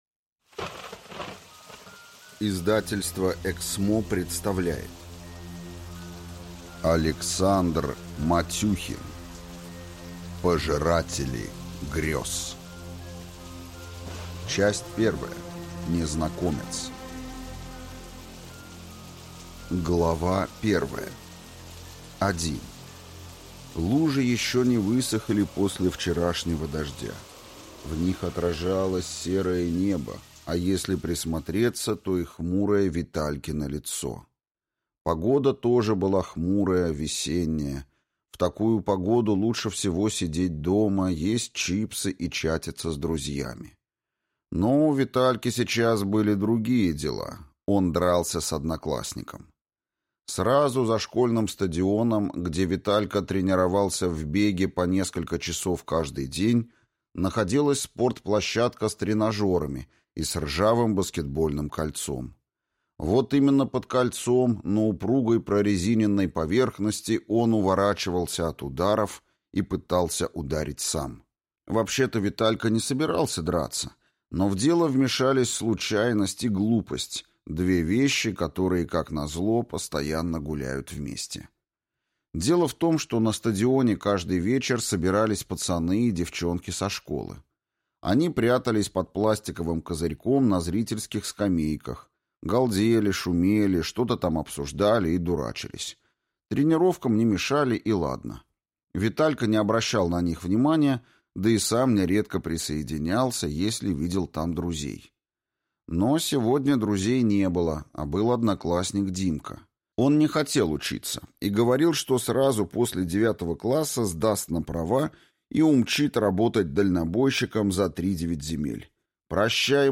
Аудиокнига Пожиратели грёз | Библиотека аудиокниг